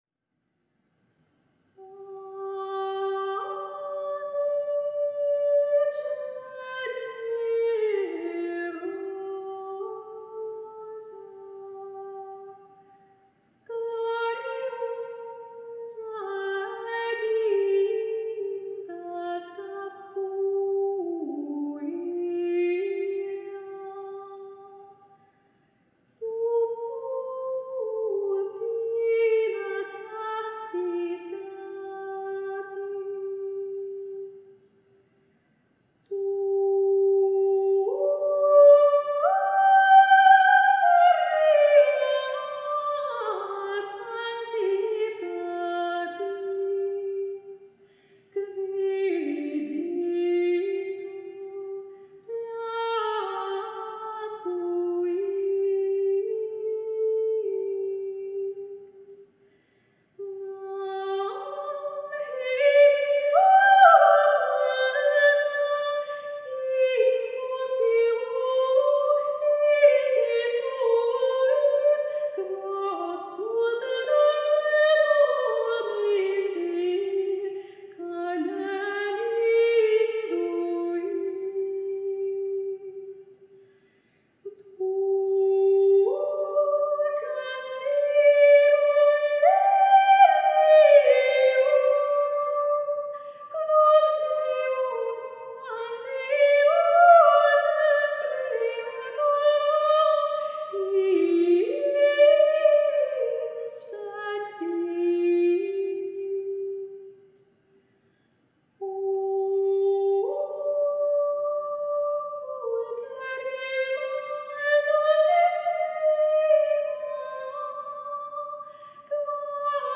soprano
Solo song: